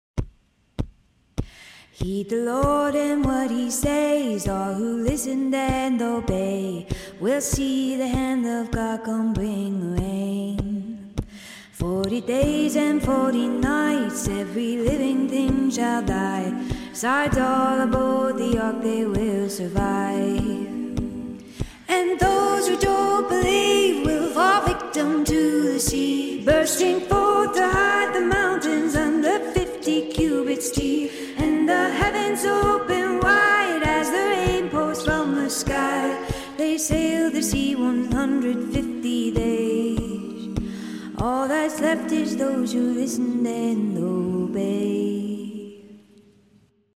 That’s why this sea shanty felt appropriate. Not only is it literally about a flood, but the might of this story feels like something sailors would pass down in songs as an old wives tail.